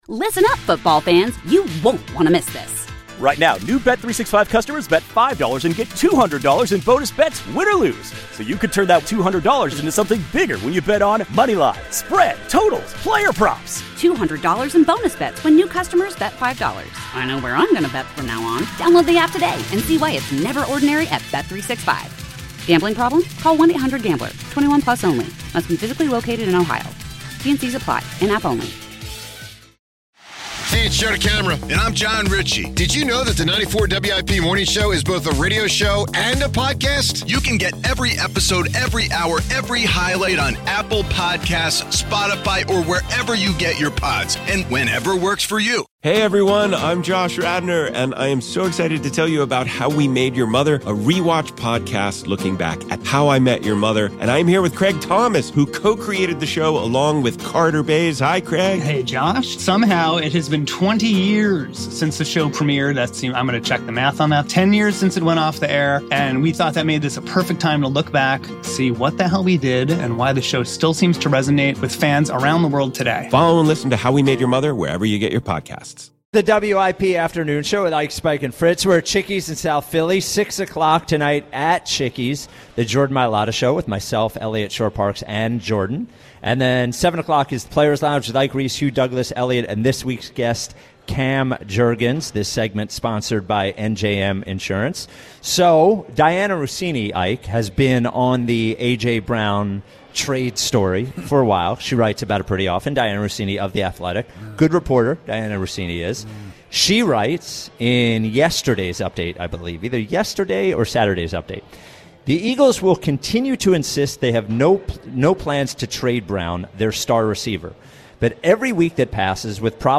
Former NFL GM Mike Mayock joins the show.
The Afternoon Show Team debates if the Eagles have run out of time or still can save their team.
They listen and react to Nick Sirianni speaking with the media for the first time since the Eagles lost brutally to the New York Giants on Thursday night. They also break down the AJ Brown drama of the week, and if there is a possibility that he could be traded mid season.